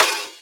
• Airy Trap Snare Drum B Key 49.wav
Royality free snare drum sample tuned to the B note.
airy-trap-snare-drum-b-key-49-69n.wav